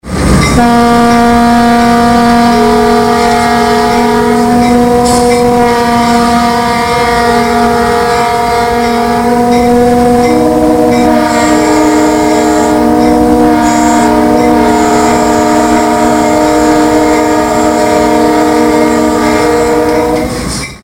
All three bells would sound when the engineer's horn valve was fully opened.
"Third generation" PM-920 horns tend to play: 247, 317, 446 Hz (approximately B, D#, A)
Horns with internal valves